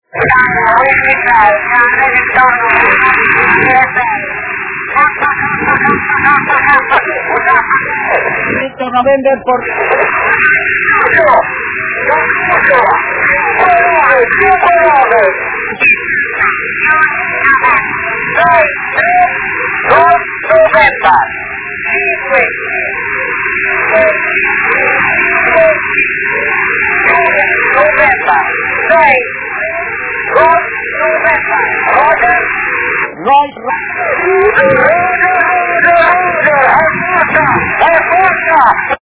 Archivos sonido de QSOs en 10 GHz SSB
827 Kms Tropo Mar